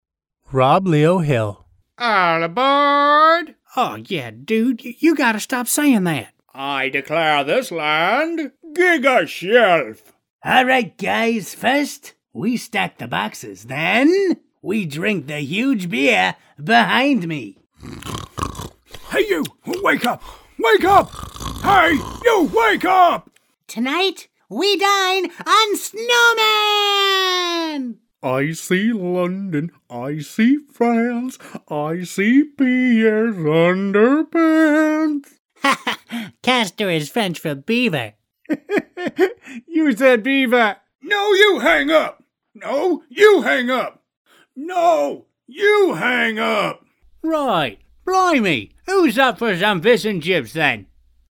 Guy Next Door, Young, Energetic and Natural.
Sprechprobe: Sonstiges (Muttersprache):
My home studio allows me to offer my clients profession recordings quickly and efficiently.